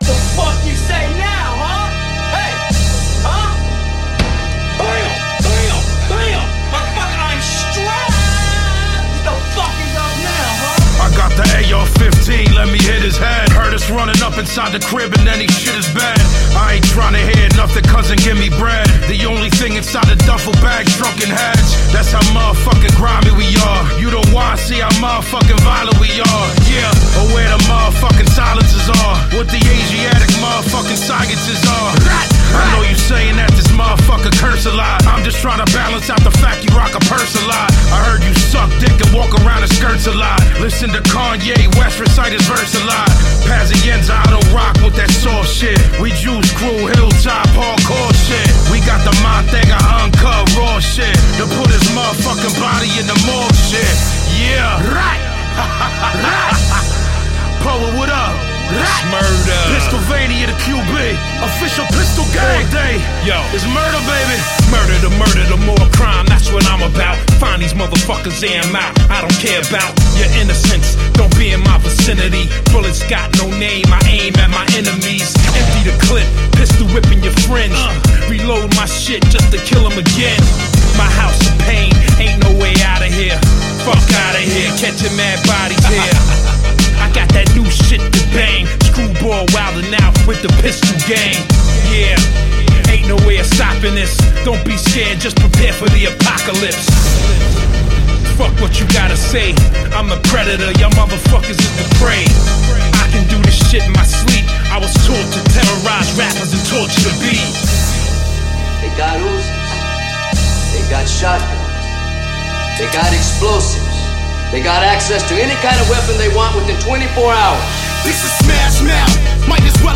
Some old fashioned, NYC goon rap.